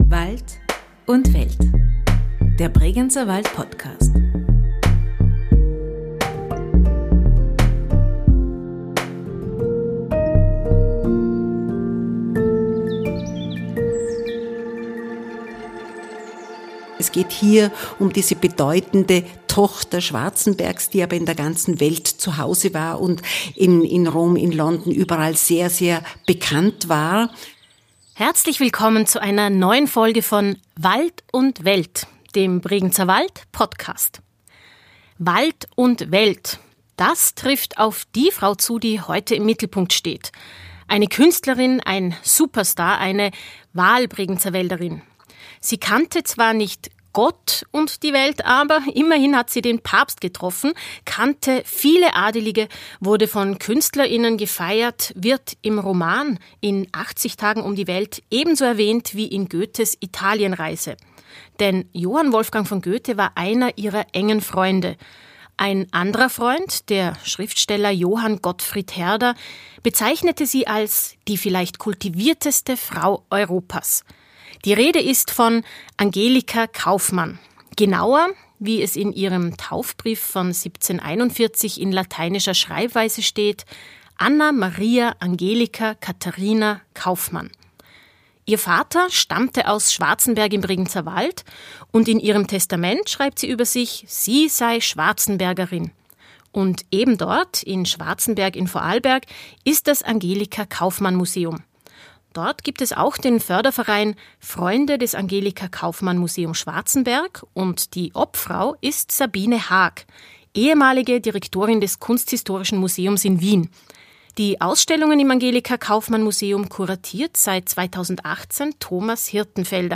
Ein Gespräch über ihr Leben und Werk – und ihre enge Beziehung zum Bregenzerwald.